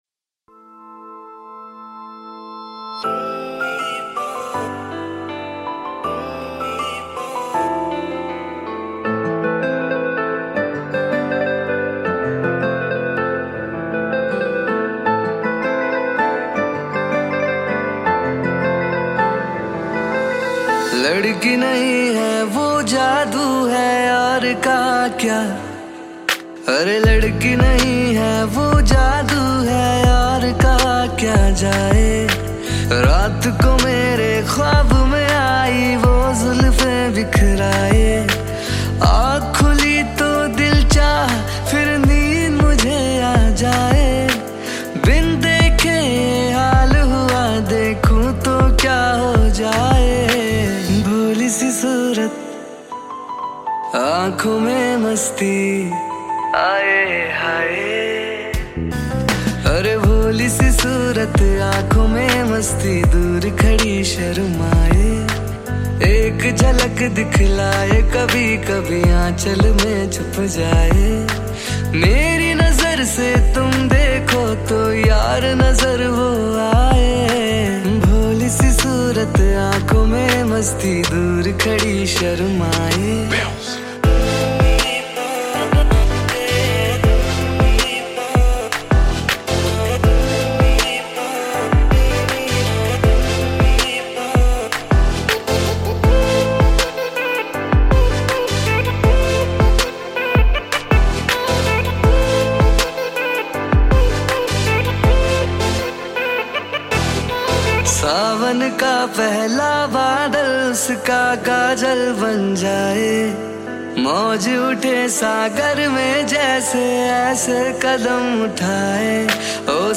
Category New Cover Mp3 Songs 2021 Singer(s